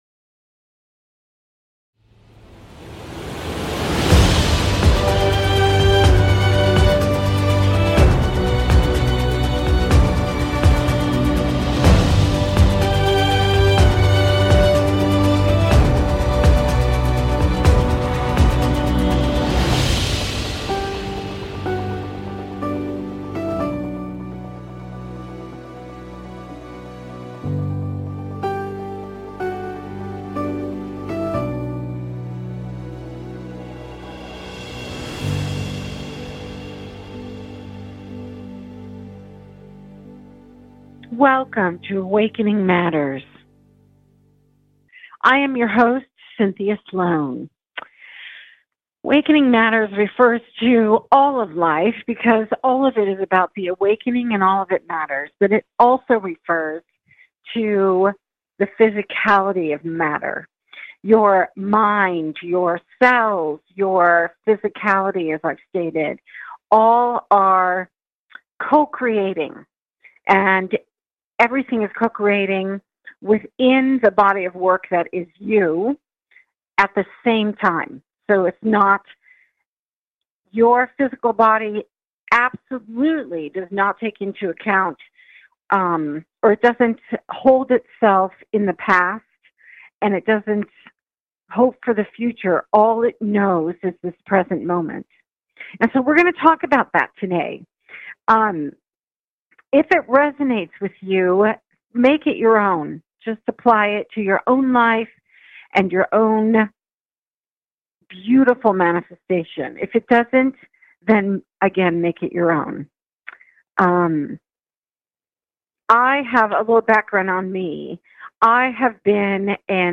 Talk Show Episode
A spiritual dialogue that invites divine wisdom, joy and laughter.